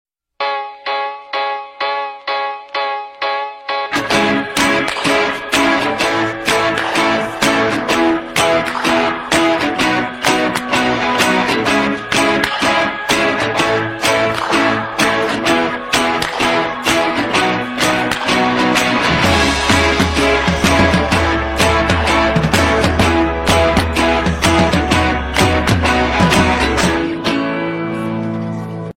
El tono de llamada iPhone sound effects free download